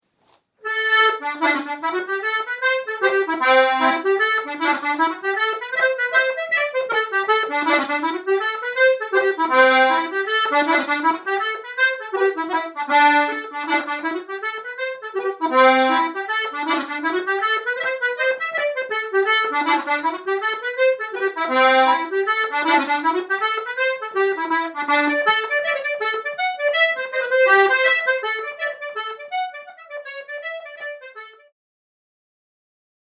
Button Accordion - Easy Online Lessons - OAIM
Button-Accordion.mp3